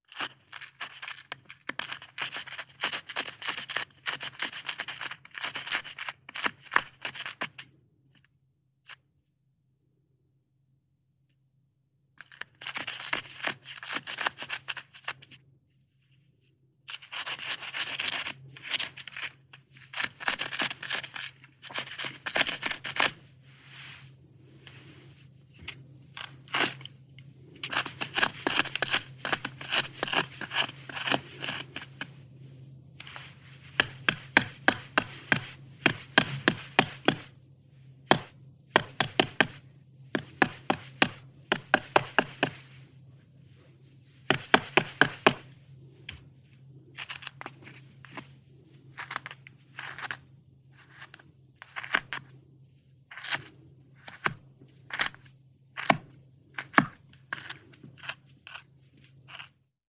Biber-Geraeusche-Wildtiere-in-Europa.wav